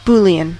Boolean: Wikimedia Commons US English Pronunciations
En-us-Boolean.WAV